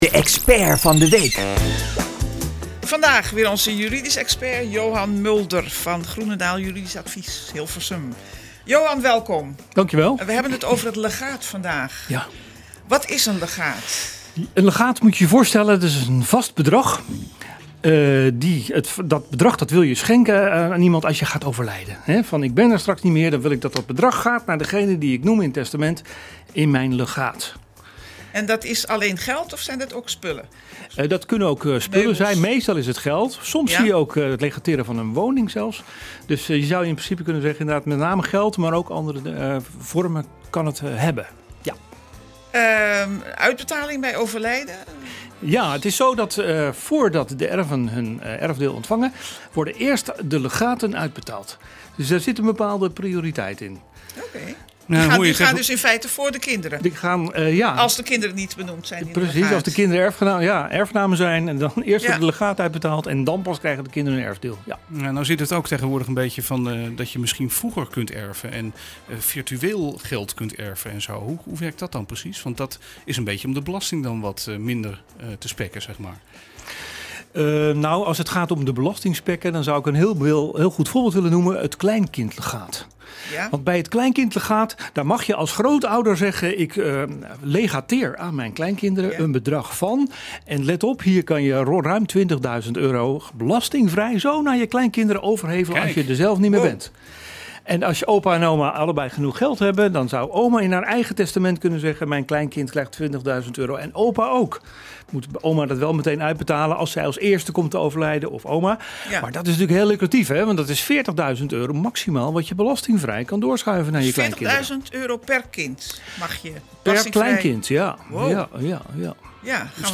Dit keer praten we met juridisch expert